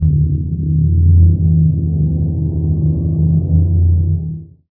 cent_hover.wav